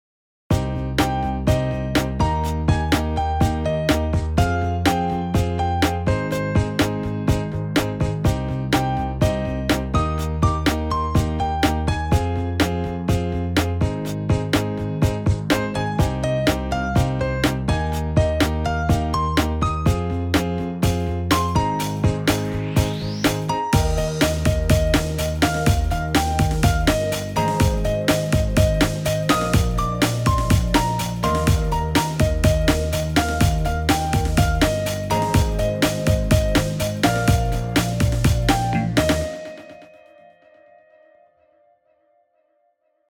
in Eb guide for tenor